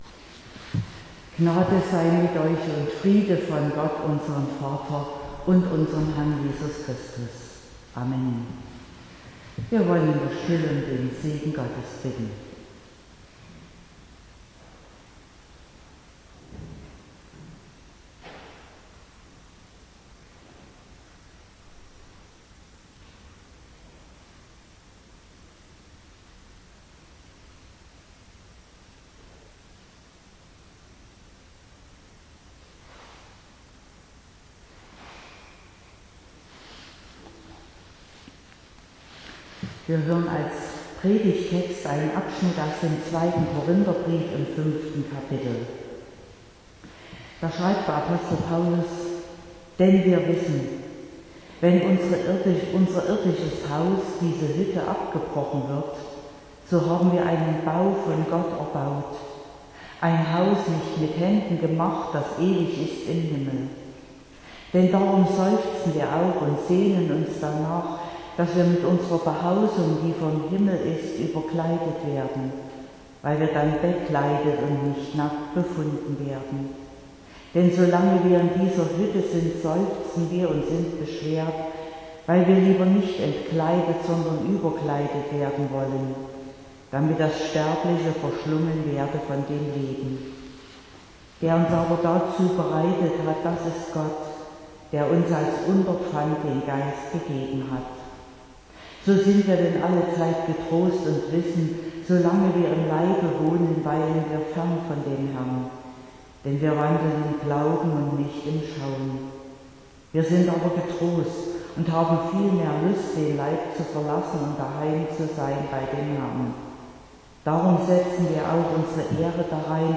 14.11.2021 – Gottesdienst
Predigt (Audio): 2021-11-14_Ewigkeit_im_Herzen.mp3 (27,6 MB)